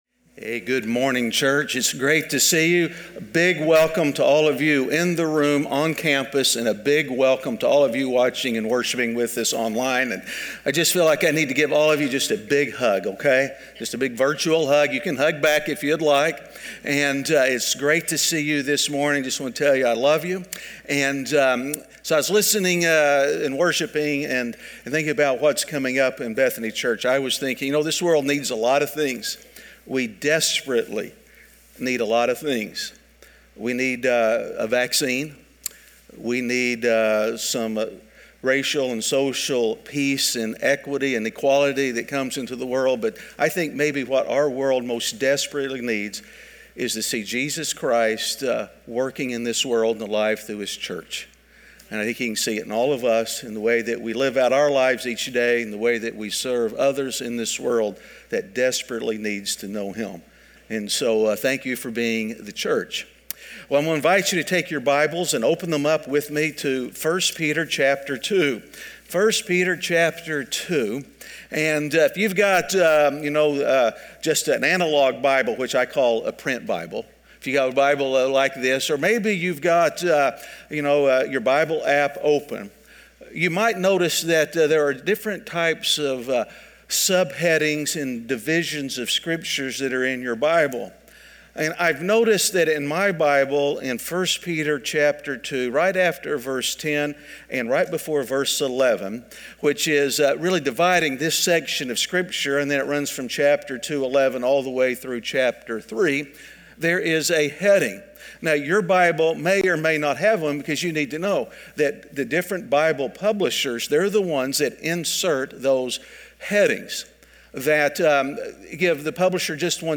Standing Firm In Shaky Times (Week 8) - Sermon.mp3